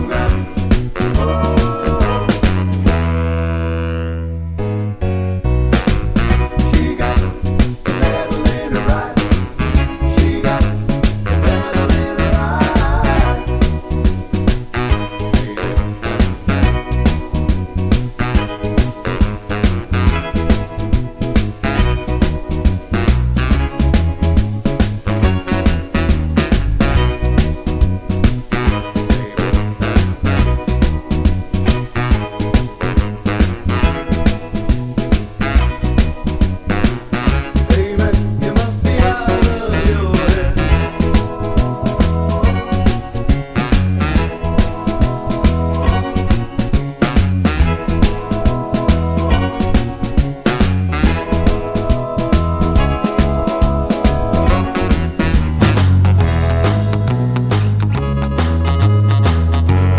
Glam Rock